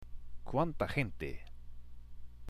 ＜発音と日本語＞